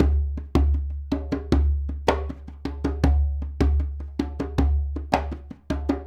Djembe 04.wav